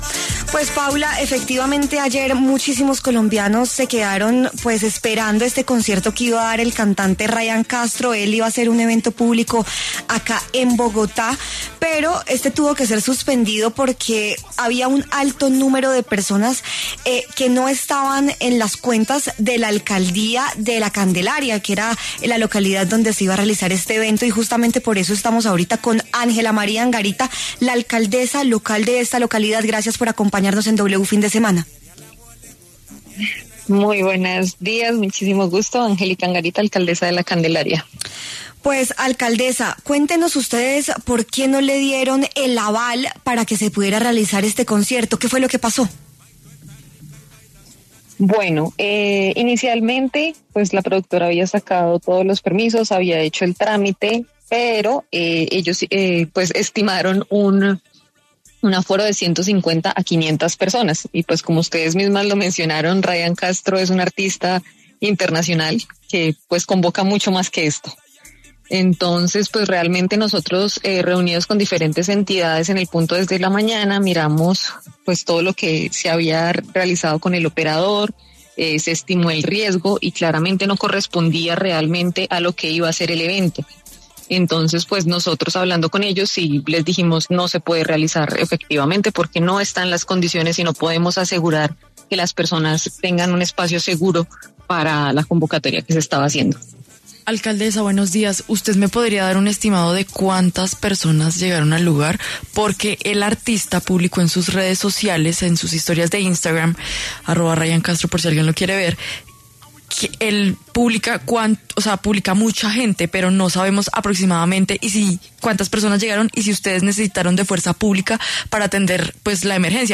W Fin De Semana conversó con la alcaldesa de La Candelaria, Ángela María Angarita, quien explicó por qué no se pudo realizar el evento de Ryan Castro.